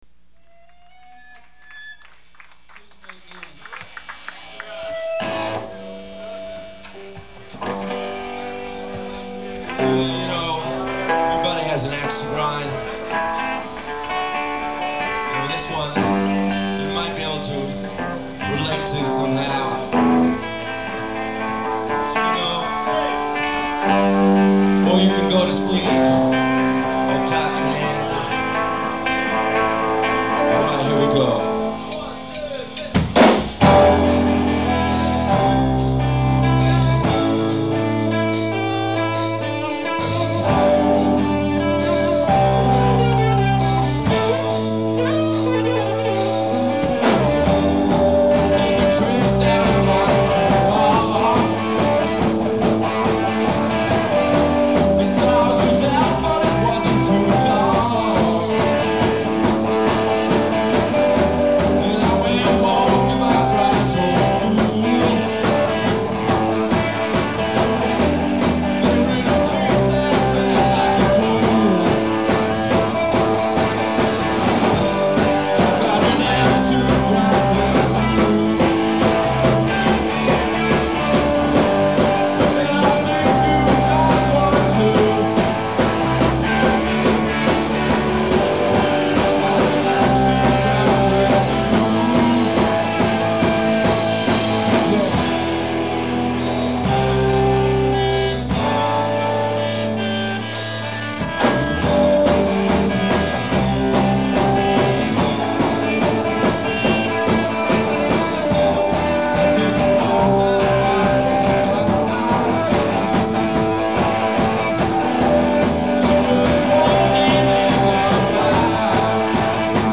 live Miami 1985